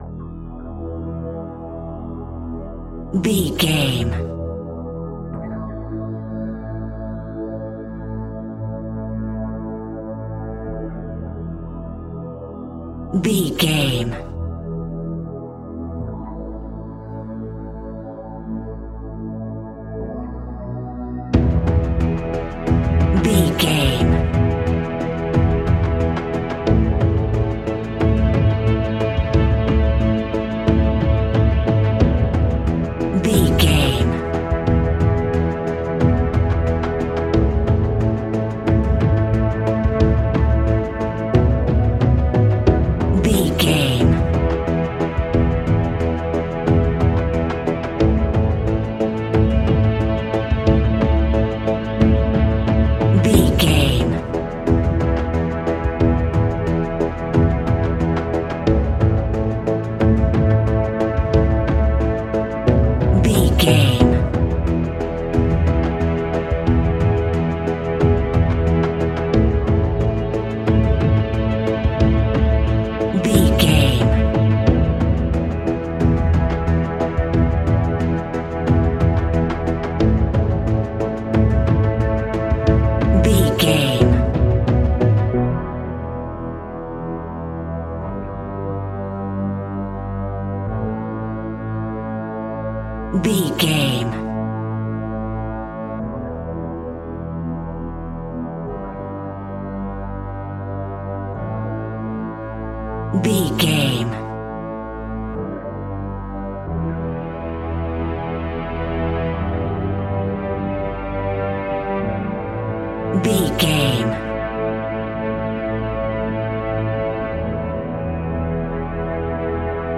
Aeolian/Minor
scary
ominous
dark
suspense
haunting
eerie
percussion
synthesizer
instrumentals
mysterious
horror music
Horror Pads
Horror Synths